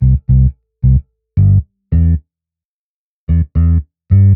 Sound_11648_Chorused_Bass.ogg